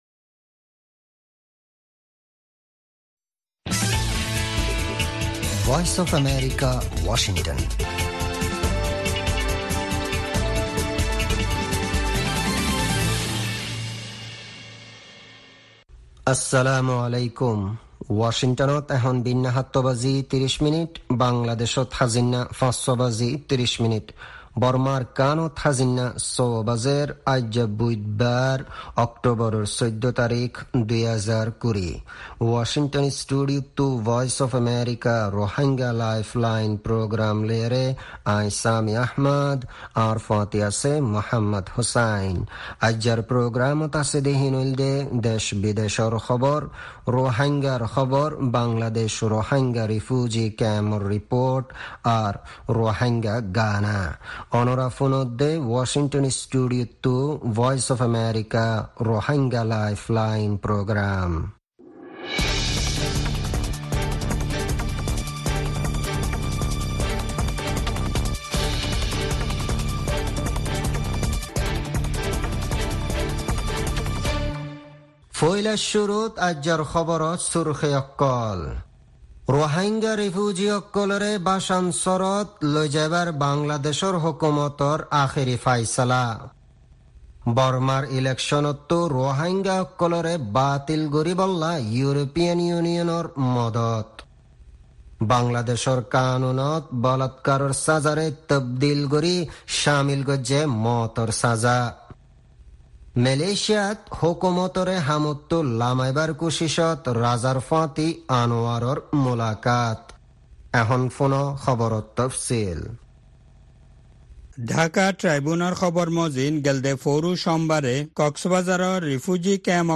Rohingya Broadcast